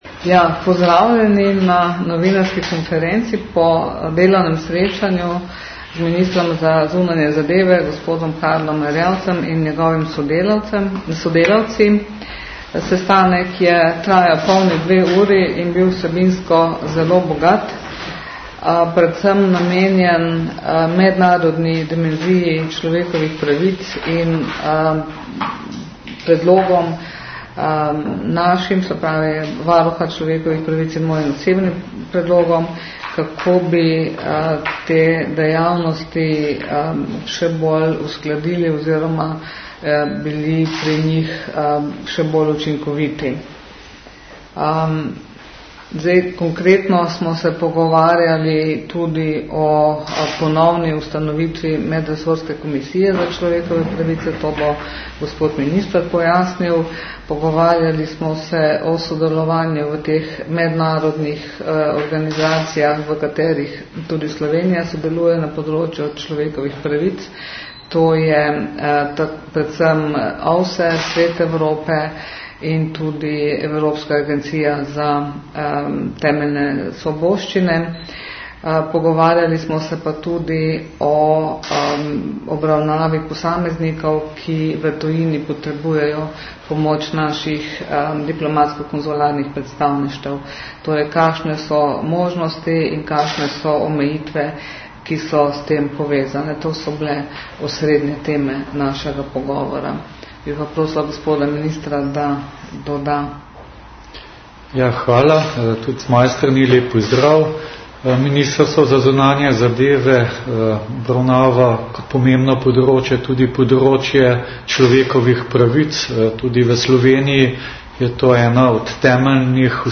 Zvočni posnetek izjave.
Po srečanju sta varuhinja in minister v izjavi za javnost na krajši novinarski konferenci predstavila vsebino pogovora.